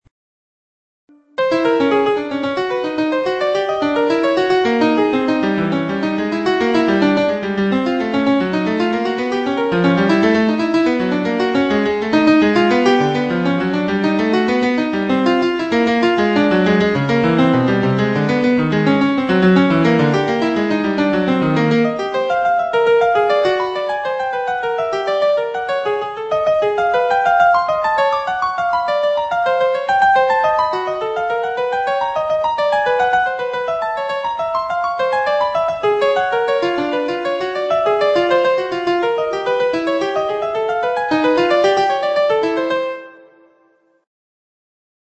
(The timing and dynamics are composed by the program and played by a MIDI player.)